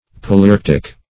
Search Result for " palearctic" : The Collaborative International Dictionary of English v.0.48: Palearctic \Pa`le*arc"tic\, a. [Paleo- + arctic.]
palearctic.mp3